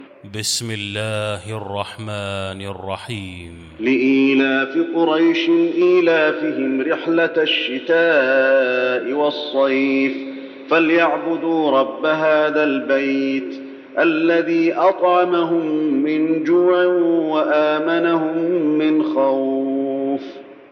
المكان: المسجد النبوي قريش